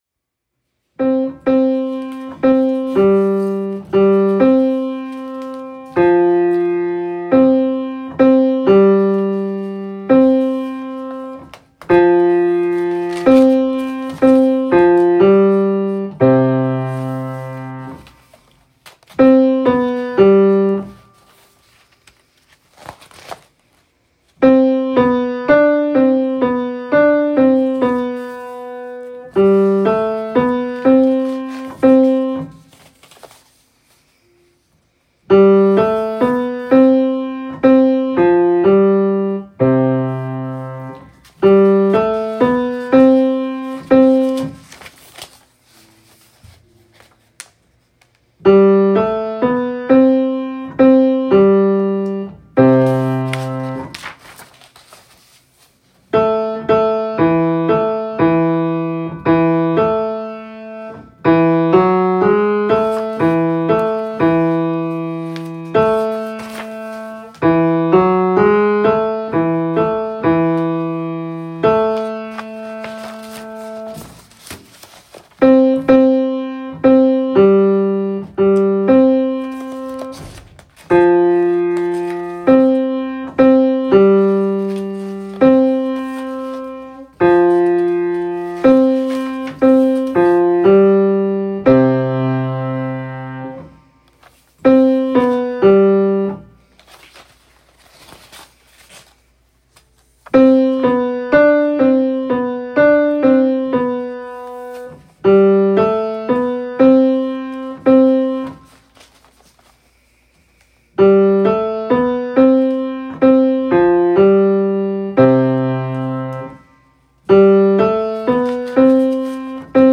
Ritorni Al Nostro Cor Tenor and Bass.m4a